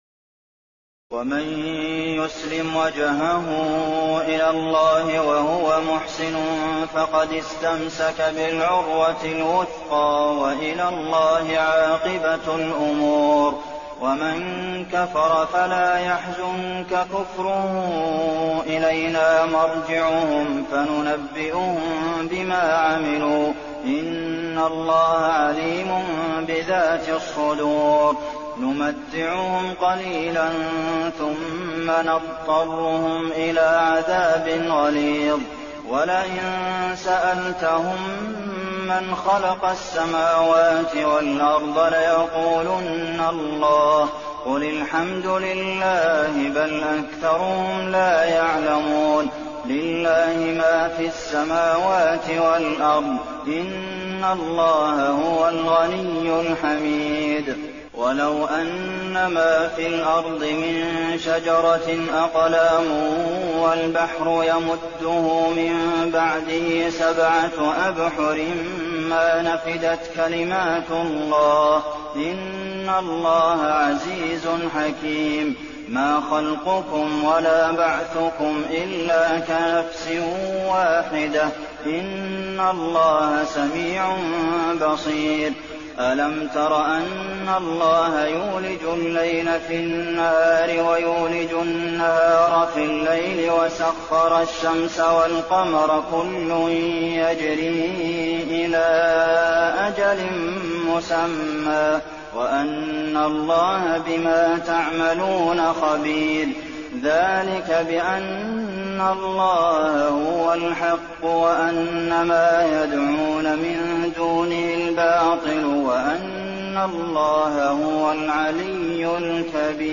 تراويح الليلة العشرون رمضان 1422هـ من سور لقمان (22-34) و السجدة و الأحزاب (1-27) Taraweeh 20 st night Ramadan 1422H from Surah Luqman and As-Sajda and Al-Ahzaab > تراويح الحرم النبوي عام 1422 🕌 > التراويح - تلاوات الحرمين